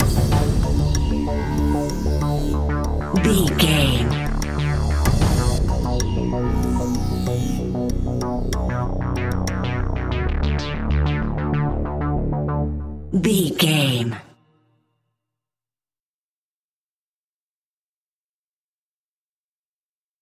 Aeolian/Minor
scary
tension
ominous
dark
disturbing
haunting
eerie
industrial
cello
synthesiser
percussion
drums
horror music